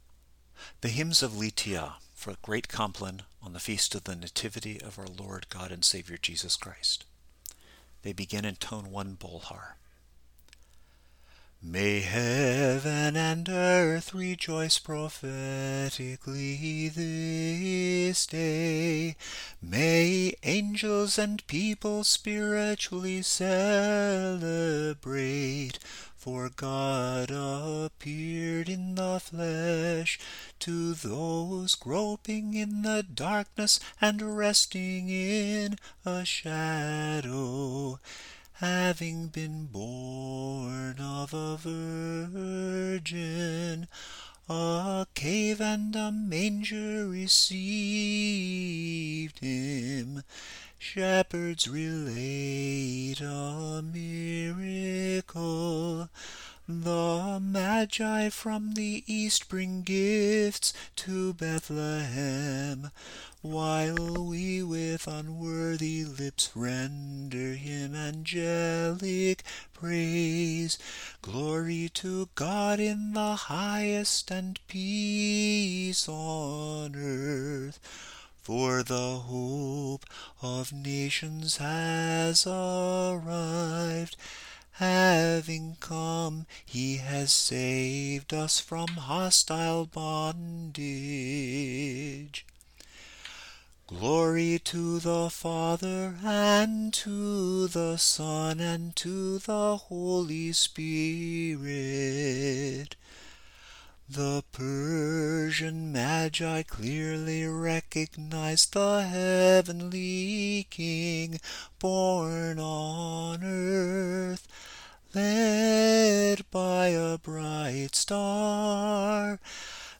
The Litija hymns for Christmas are in tones 1, 5, and 6.
There is no Bolhar melody for Tone 6, so the last of the Litija hymns is sung to the much easier samohlasen melody in Tone 6.
Listen to the hymns of Litija for the Nativity (Bolhar melodies)